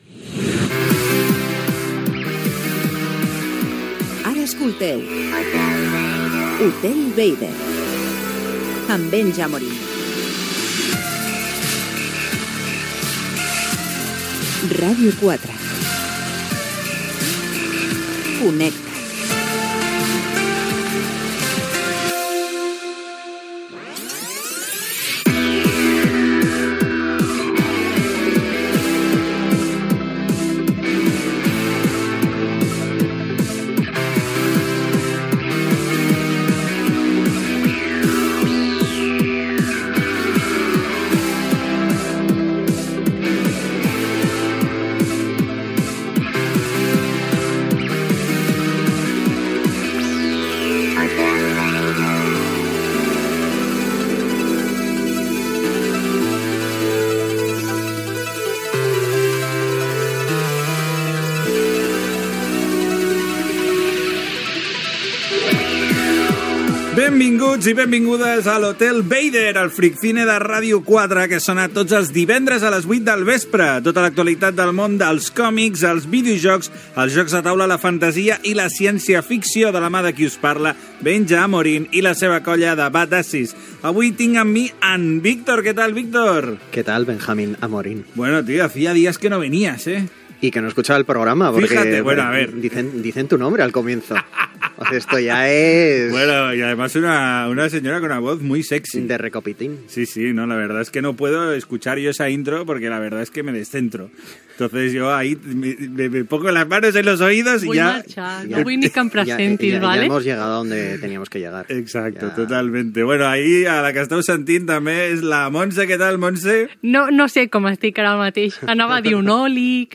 Indicatiu del programa, careta, presentació de l'equip dels invitats del programa, presentació de la nova col·lecció del joc de cartes japonès "Kamigawa: Neon Dynasty", torna "Futurama" a la plataforma Hulu
Entreteniment
FM